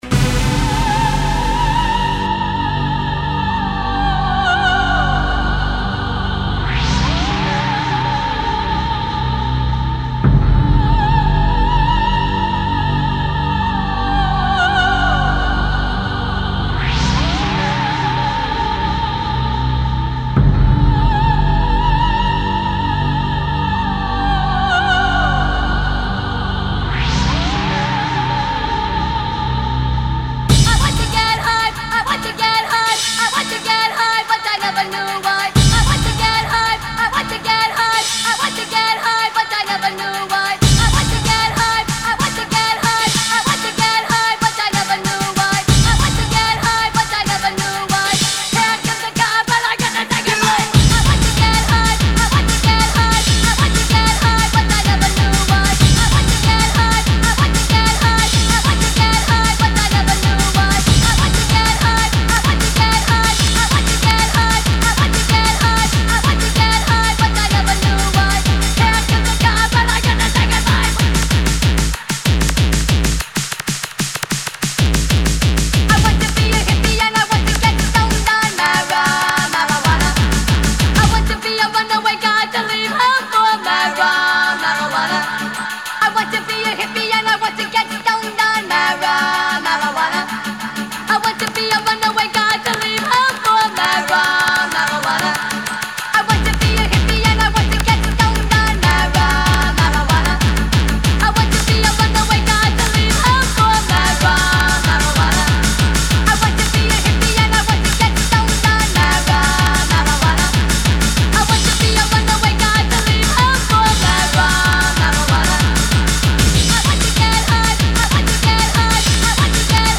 Happy Hardcore?!